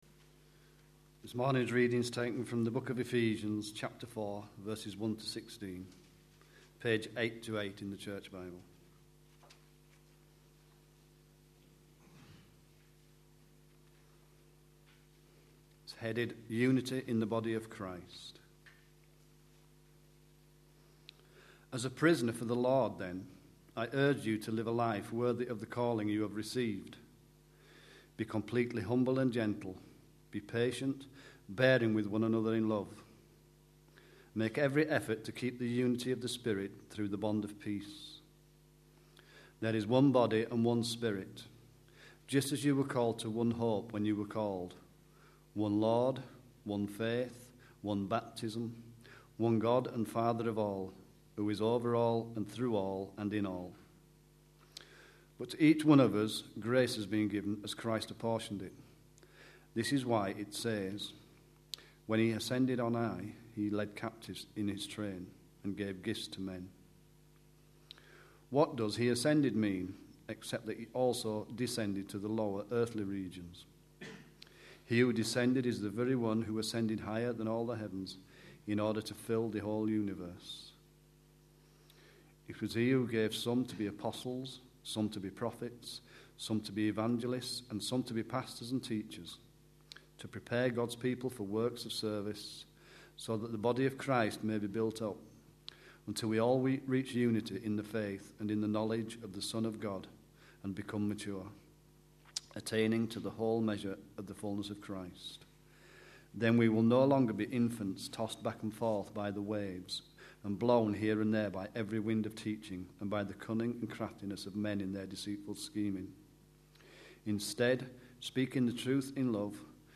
A sermon preached on 18th September, 2011, as part of our Distinctives series.